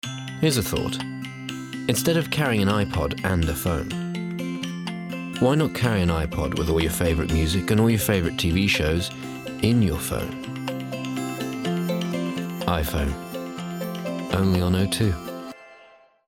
::download:: I-Phone Commercial
Рекламко айфона.